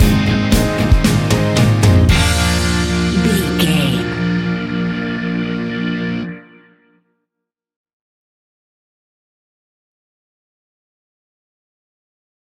Fast paced
Mixolydian
B♭
pop rock
fun
energetic
uplifting
acoustic guitars
drums
bass guitar
electric guitar
piano
organ